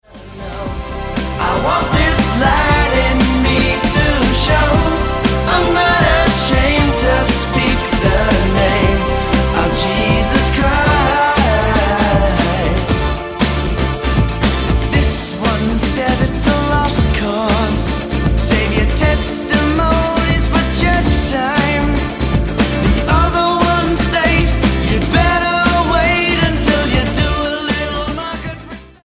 STYLE: Rock
Timelessly catchy pop-rock.